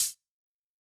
UHH_ElectroHatA_Hit-21.wav